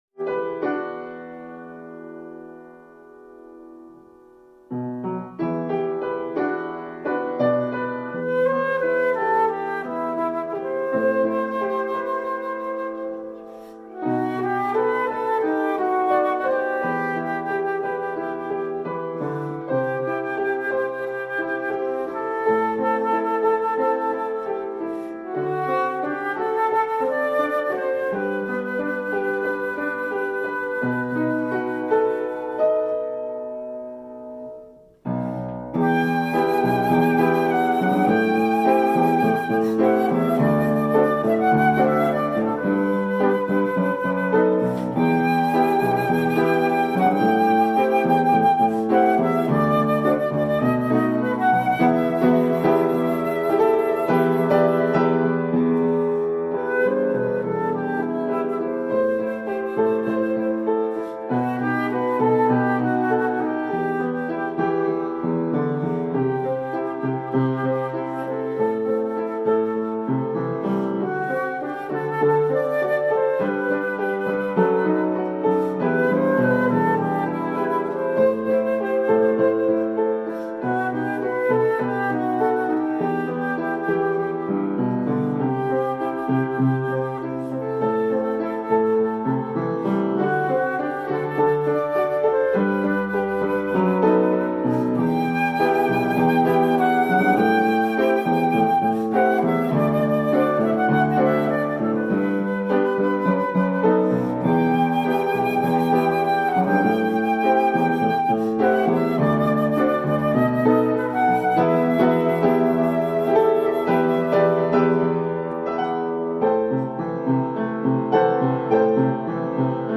フルート
・フルートはなにか「よそよそしい」感じがする